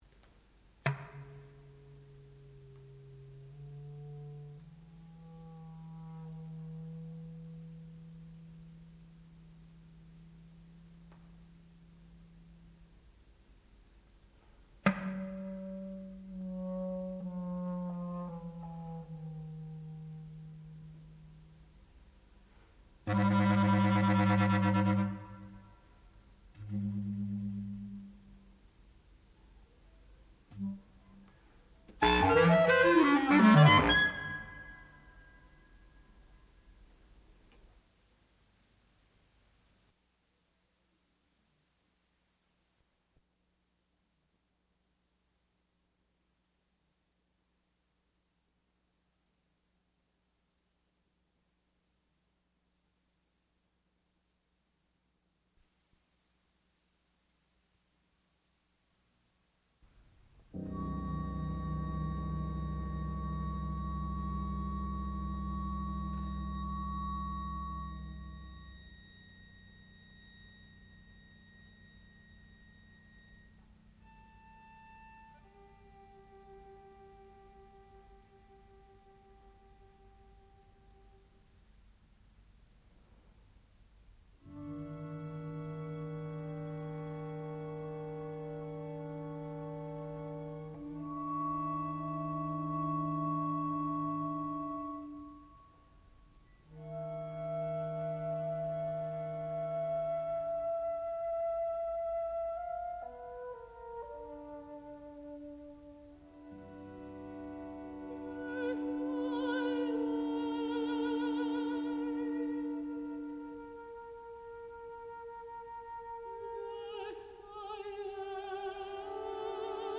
A Childs Garden of Verses (1979), (Robert Louis Stevenson) for soprano and flute
sound recording-musical
classical music